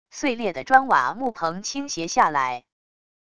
碎裂的砖瓦木棚倾斜下来wav音频